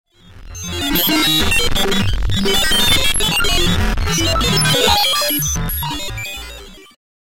Broken-computer-noises-sound-effect.mp3